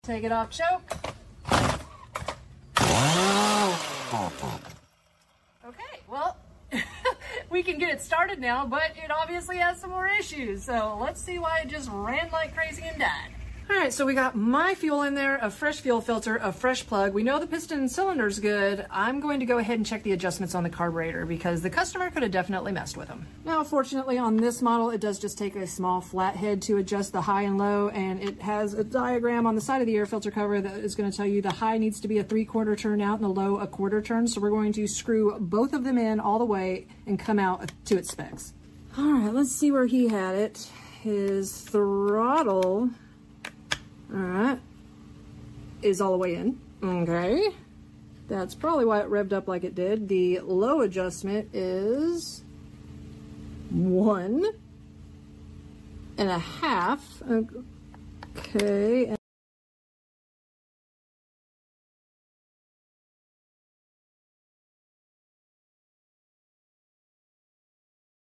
Chainsaw Extremely HARD To Pull?